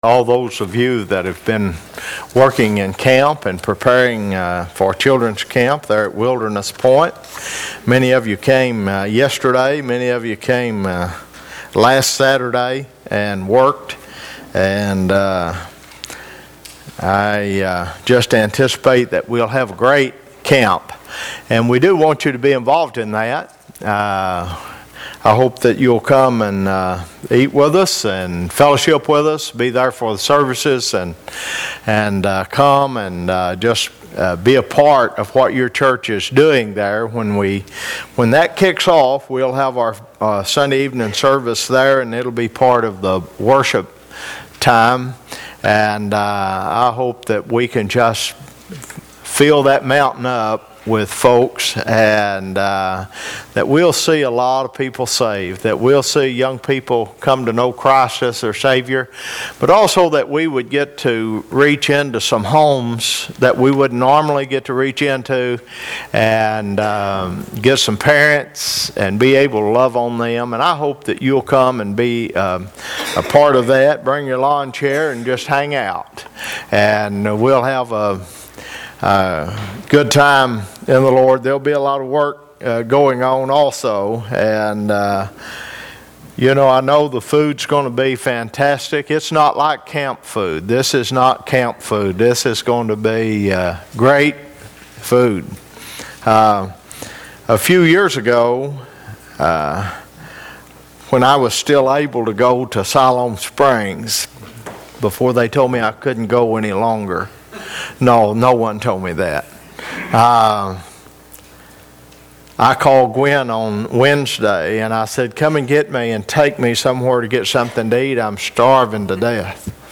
Required fields are marked * Comment * Name * Email * Website ← Newer Sermon Older Sermon →